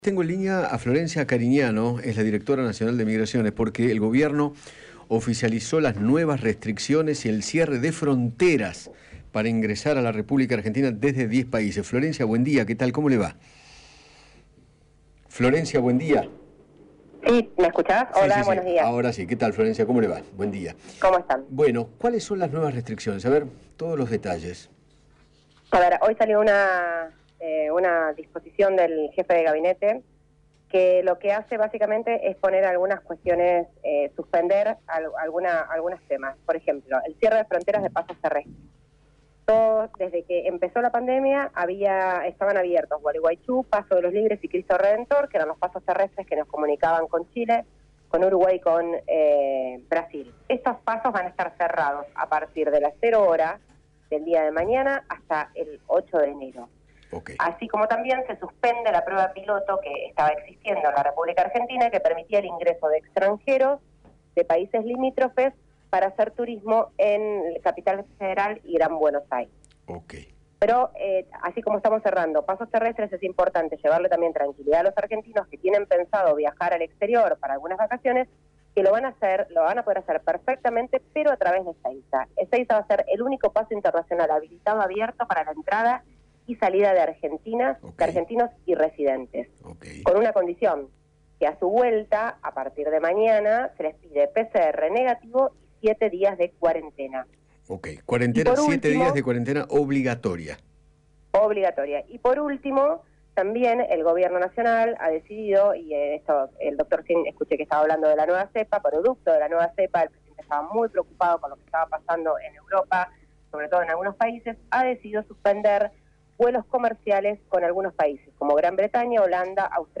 Florencia Carignano, directora nacional de Migraciones, habló con Eduardo Feinmann sobre el cierre de algunas fronteras y se refirió a las nuevas restricciones para ingresar al país.